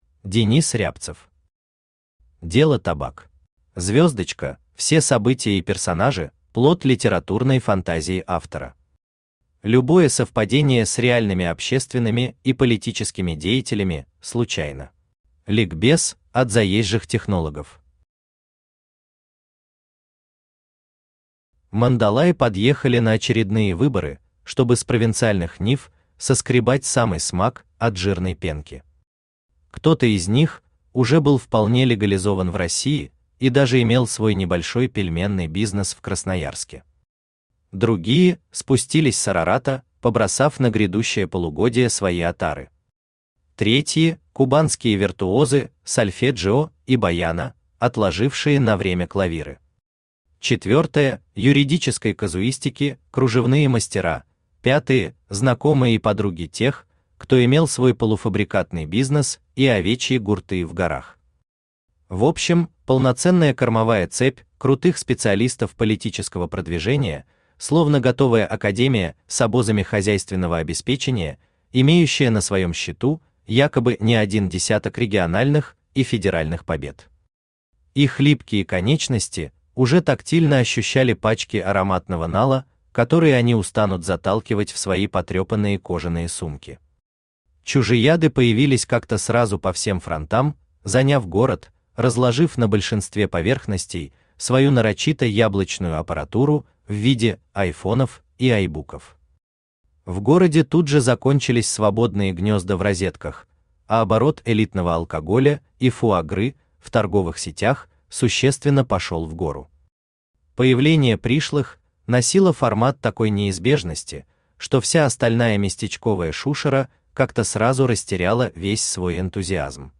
Аудиокнига Дело табак | Библиотека аудиокниг
Aудиокнига Дело табак Автор Денис Евгеньевич Рябцев Читает аудиокнигу Авточтец ЛитРес.